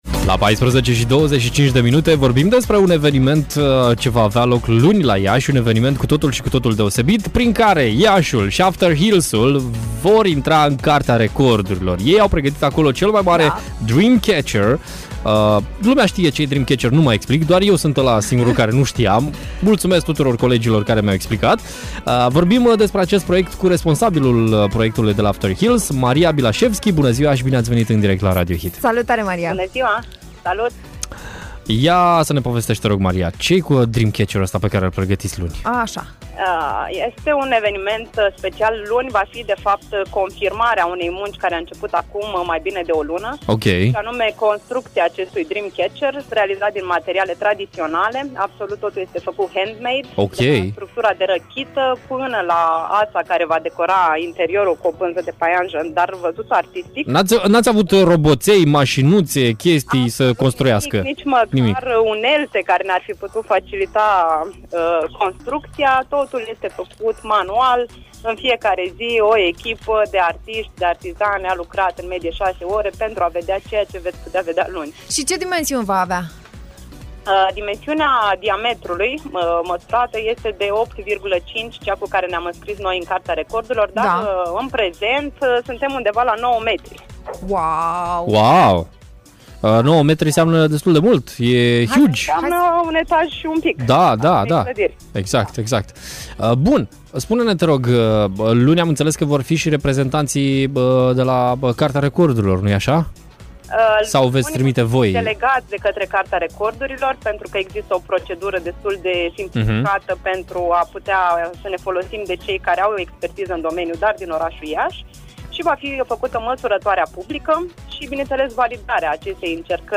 Organizatorii festivalului Afterhills vor intra luni 14 mai în Cartea Recordurilor cu cel mai mare dreamcatcher din lume! Detalii am aflat în direct la Radio Hit